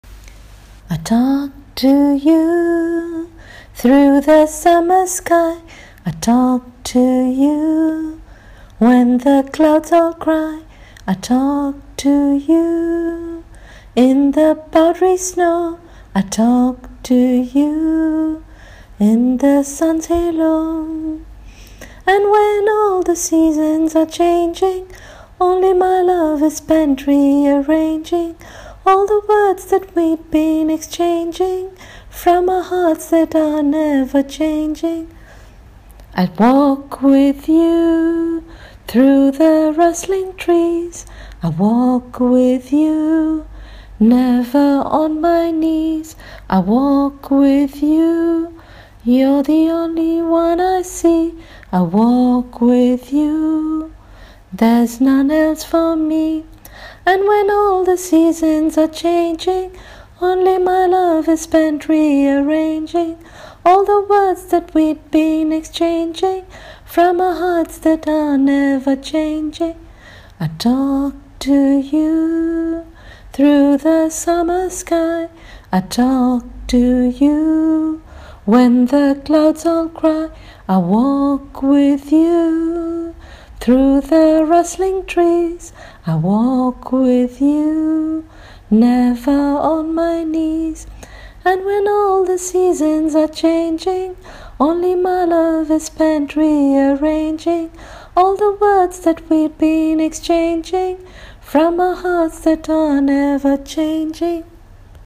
Singing of the lyrics: